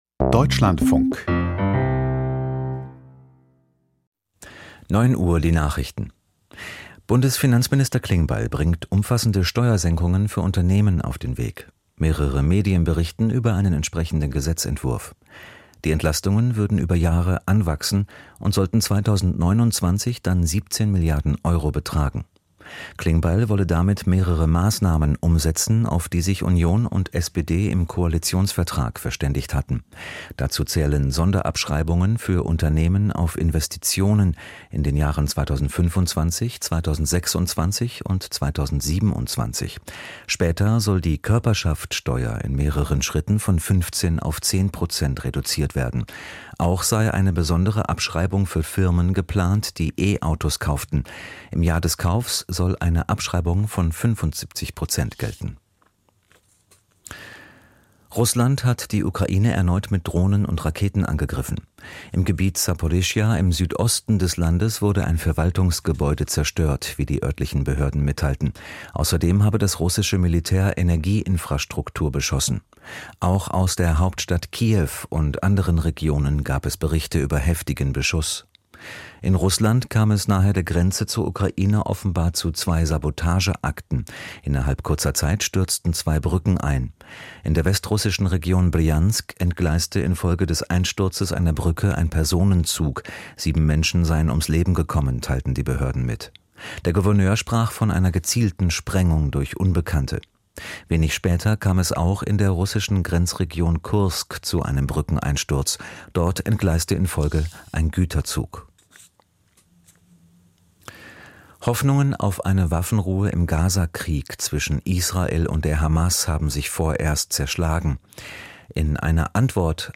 Die Nachrichten vom 01.06.2025, 09:00 Uhr
Aus der Deutschlandfunk-Nachrichtenredaktion.